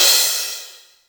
• Long Room Reverb Cymbal Sample F Key 03.wav
Royality free cymbal sound tuned to the F note. Loudest frequency: 6757Hz
long-room-reverb-cymbal-sample-f-key-03-D42.wav